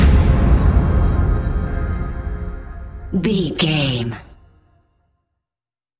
Category: Music